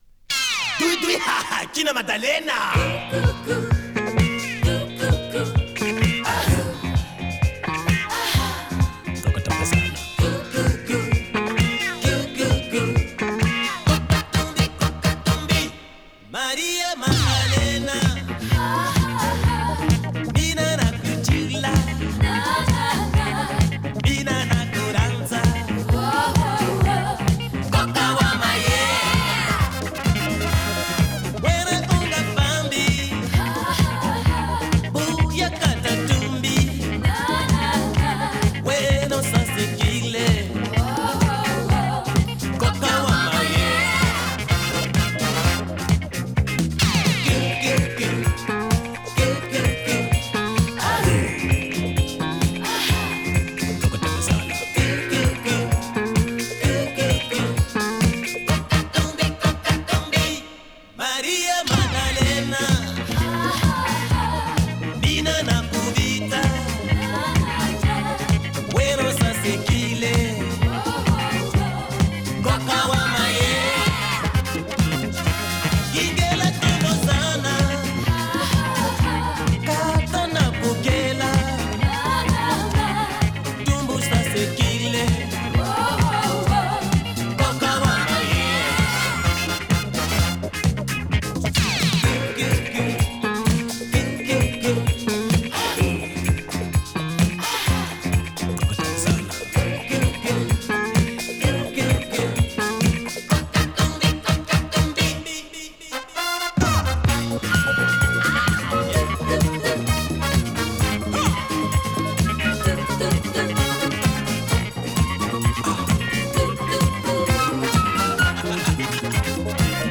Этот вариант лучше по качеству